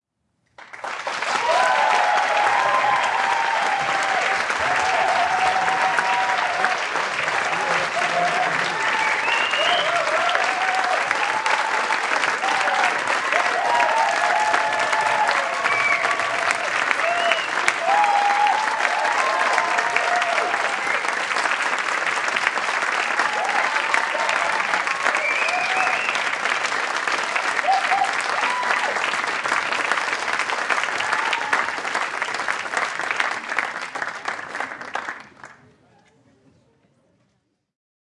描述：Fenway Park, Boston August 2015
标签： fieldrecording Red chatter applause Sox ambience Fenway baseball cheer cheering sports crowd audience Park ballpark clapping Boston people exterior stadium walla
声道立体声